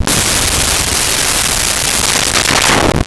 Explosion Large Flare
Explosion Large Flare.wav